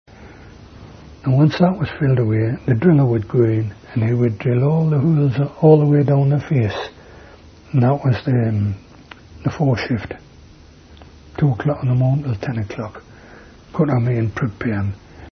Narrative History: The Drillers